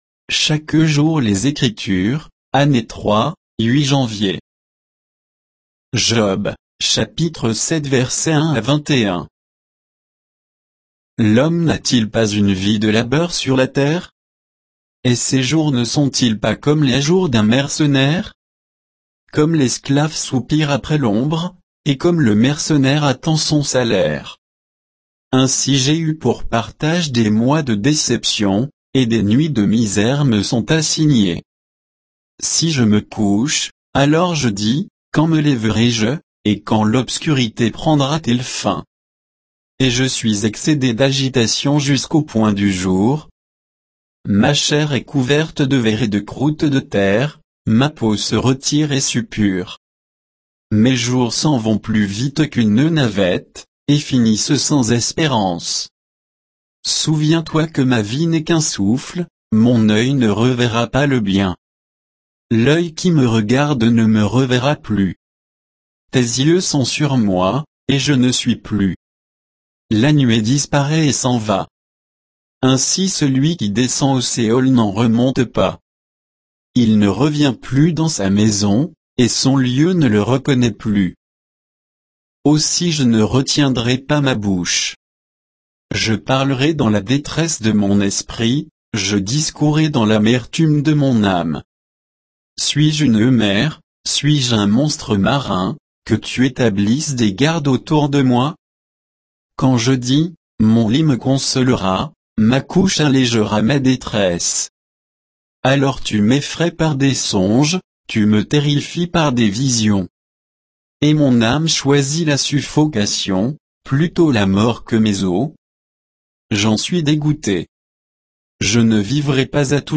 Méditation quoditienne de Chaque jour les Écritures sur Job 7